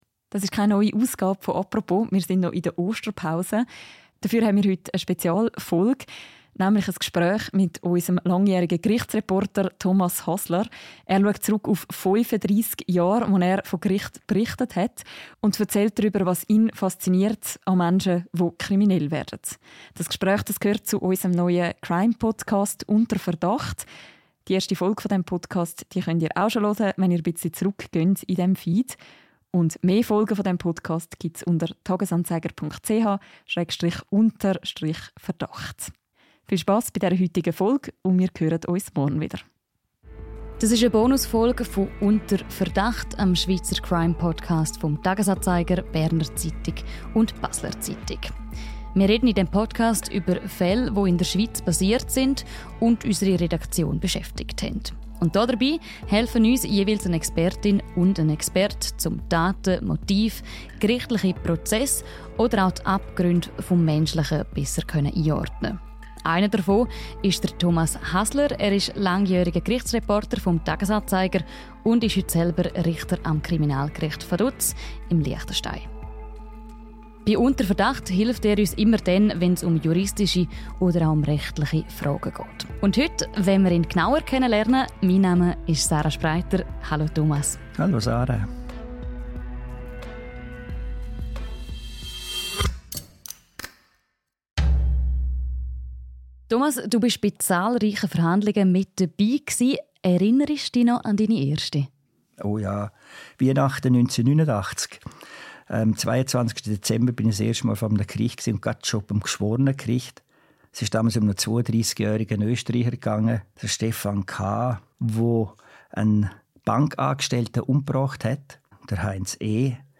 Ein Gespräch über Fairness, moralische Richter und die Faszination True Crime anlässlich dem Start unseres neuen Crime-Podcasts «Unter Verdacht».